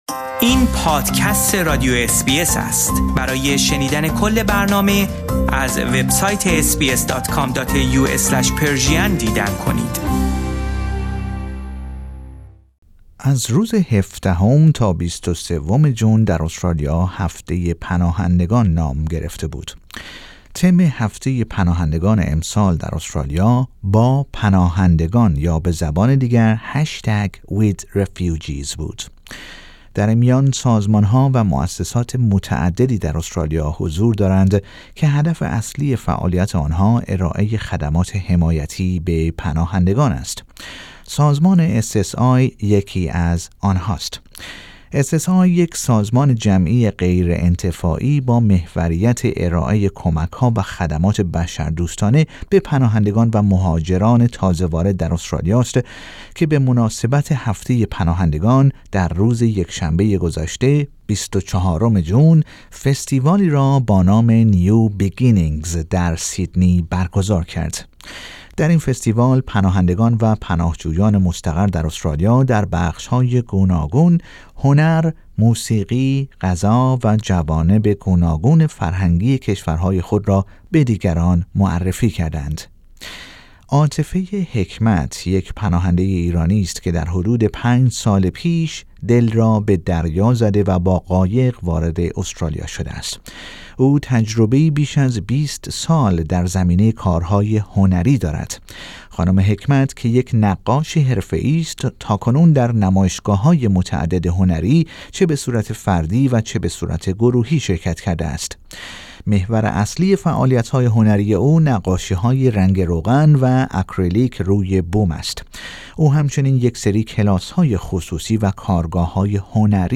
در این گزارش با دو نفر از پناهندگان ایرانی که در این فستیوال سالانه شرکت کرده اند به گفتگو پرداخته ایم.